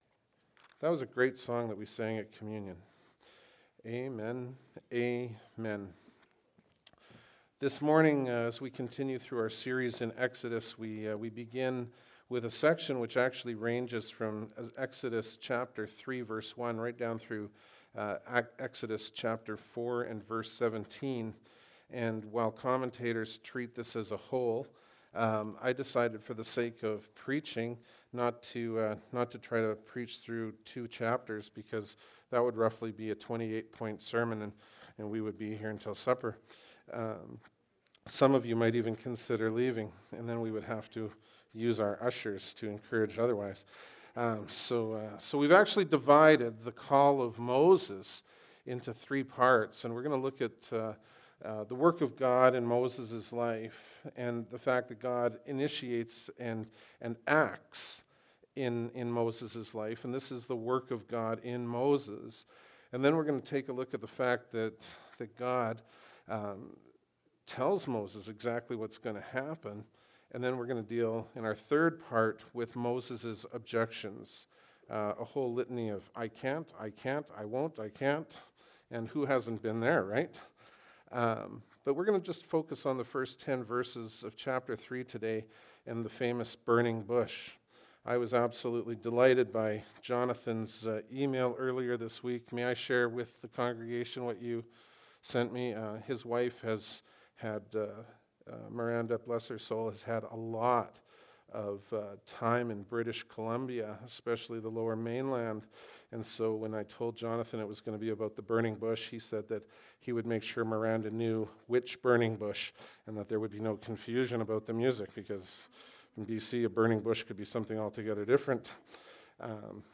Bible Text: Exodus 3:1-10 | Preacher